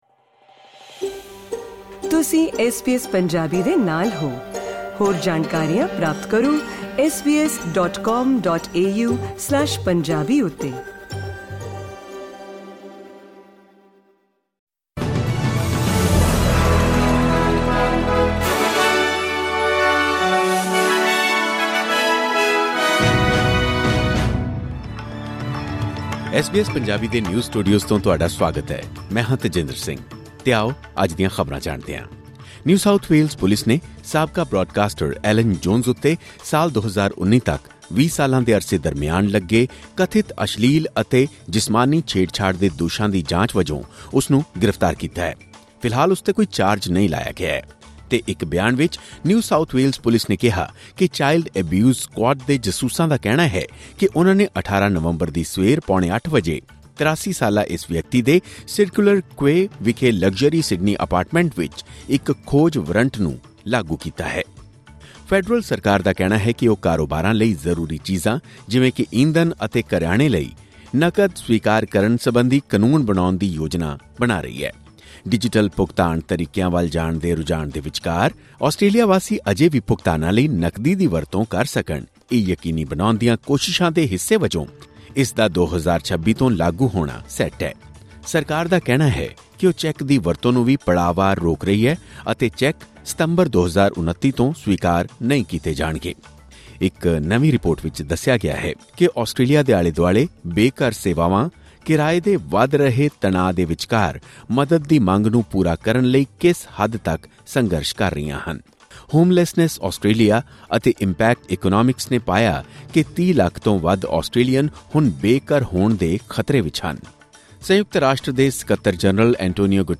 ਐਸ ਬੀ ਐਸ ਪੰਜਾਬੀ ਤੋਂ ਆਸਟ੍ਰੇਲੀਆ ਦੀਆਂ ਮੁੱਖ ਖ਼ਬਰਾਂ: 18 ਨਵੰਬਰ, 2024